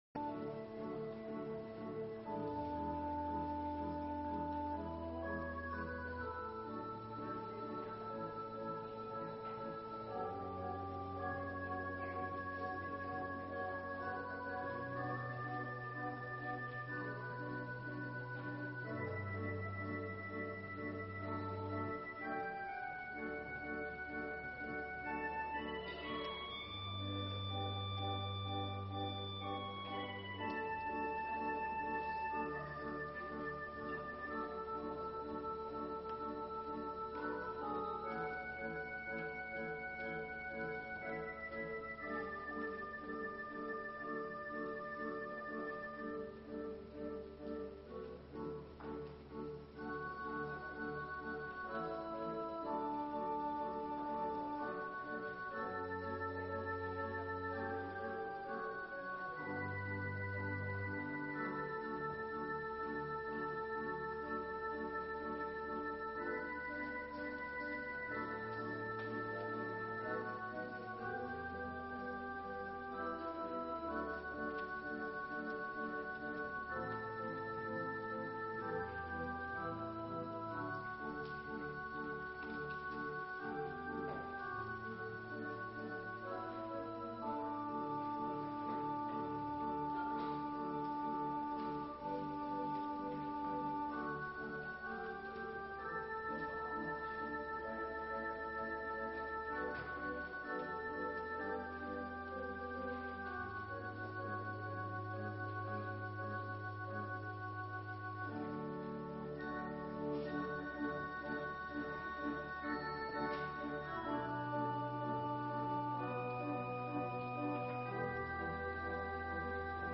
Ministry of the Word in Numbers 28:9,10
Service Type: Sunday Morning